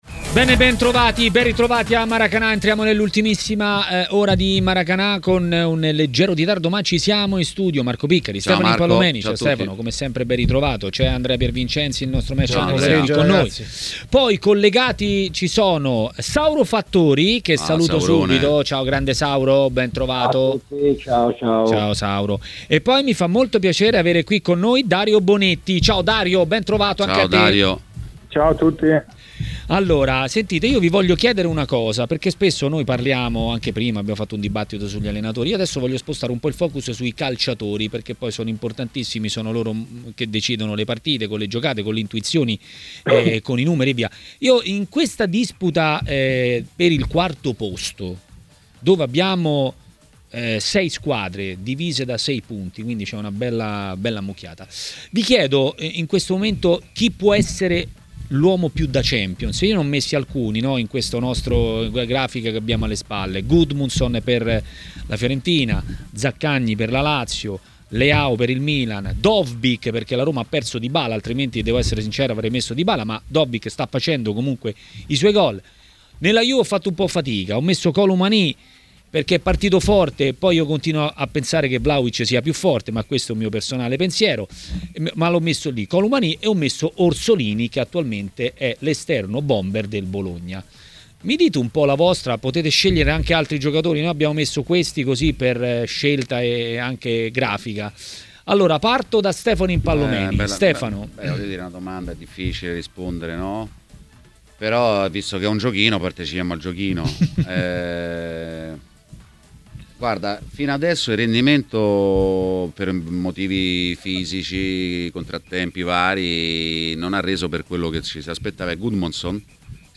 L'ex calciatore Dario Bonetti è stato ospite di TMW Radio, durante Maracanà.